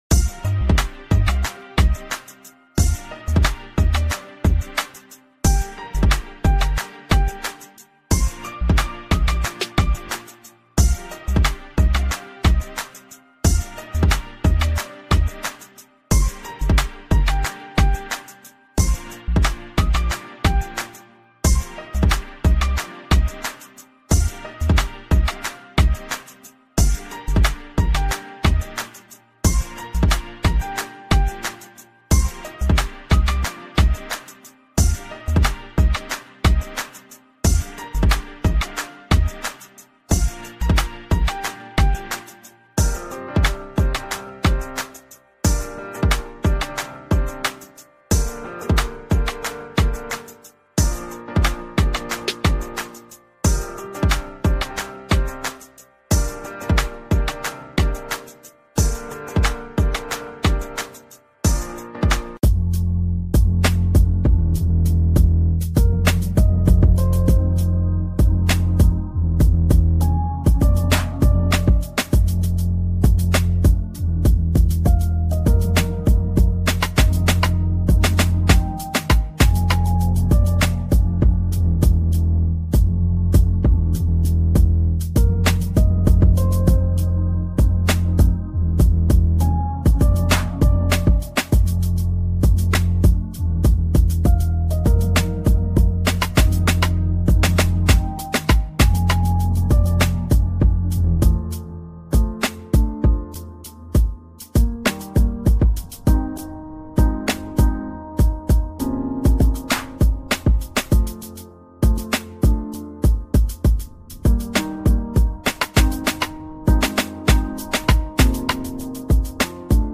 Indian Flute Sounds